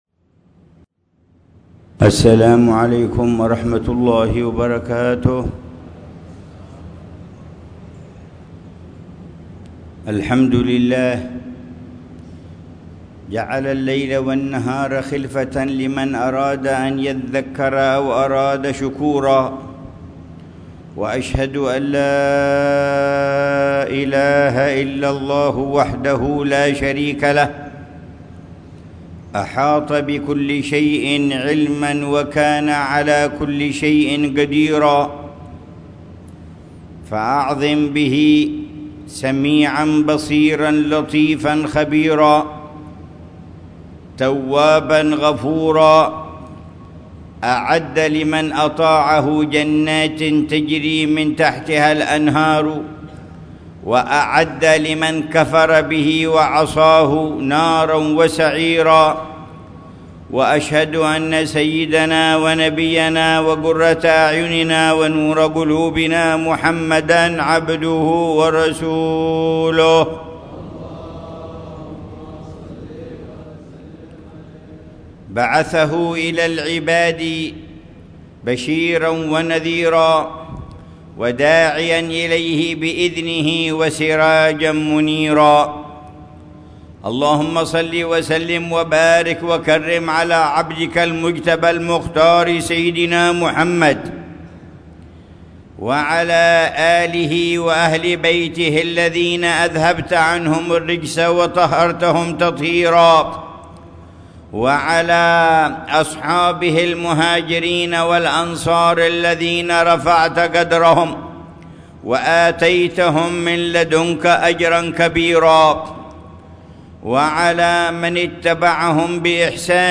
خطبة الجمعة للعلامة الحبيب عمر بن محمد بن حفيظ في جامع الإحسان في حارة حصن عوض،بمدينة تريم، 6 شوال 1446هـ بعنوان: